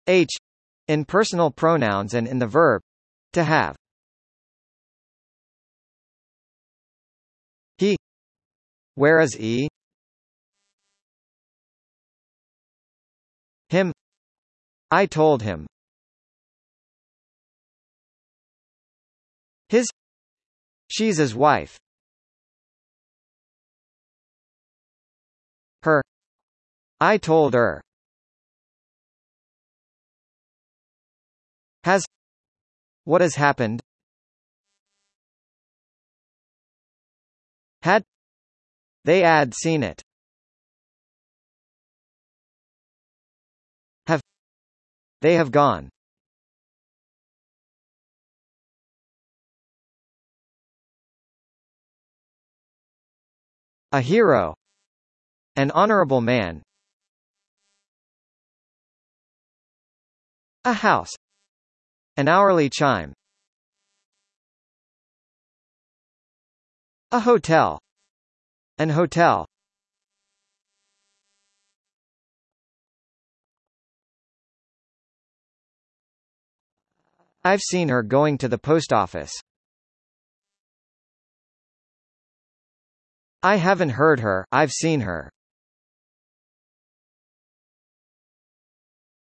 US